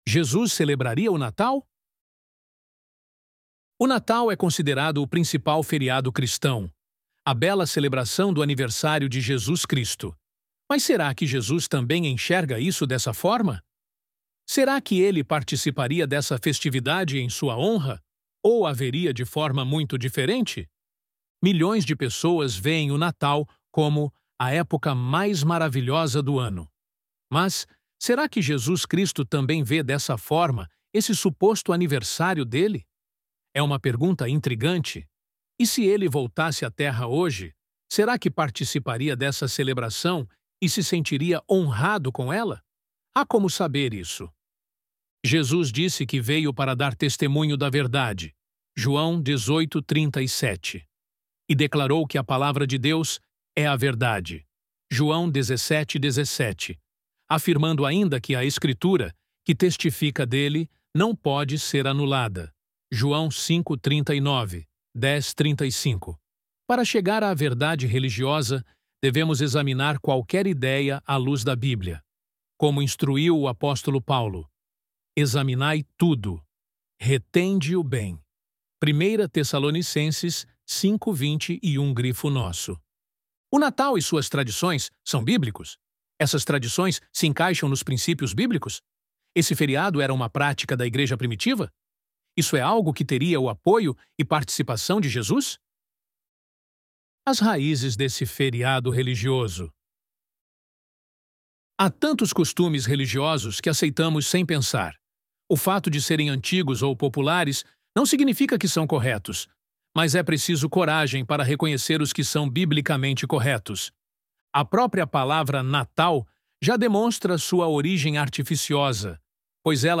ElevenLabs_Jesus_Celebraria_o_Natal_.mp3